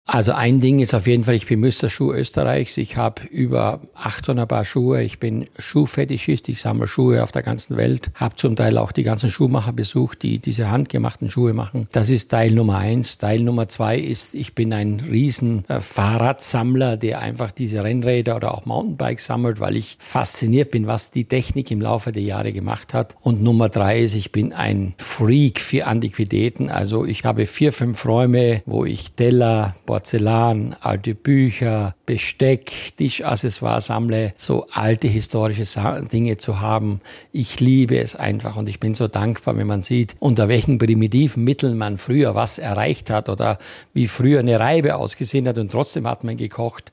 SWR3 Interview 3 Dinge über Johann, die ihr noch nicht wusstet